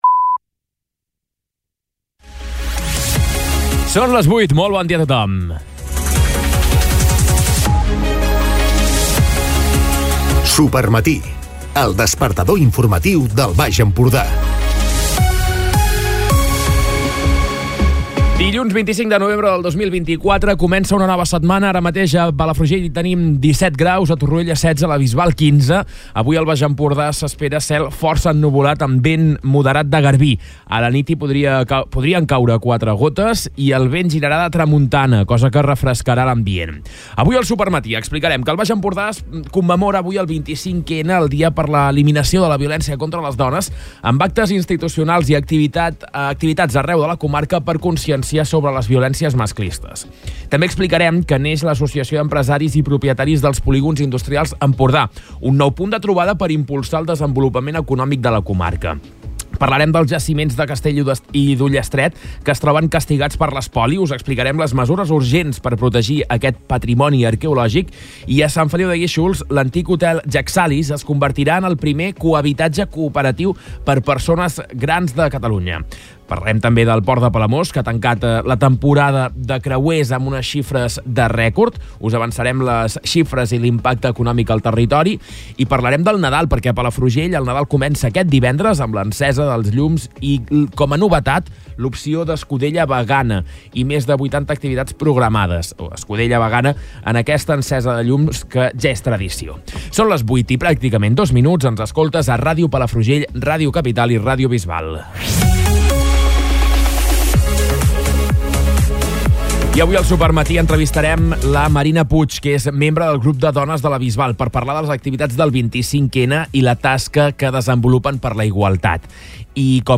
Escolta l'informatiu d'aquest dilluns